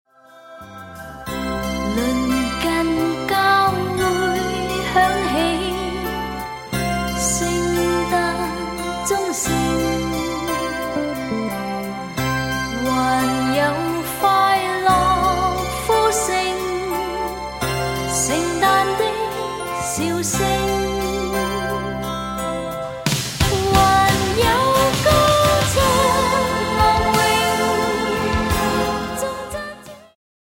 充滿動感和時代感
有伴奏音樂版本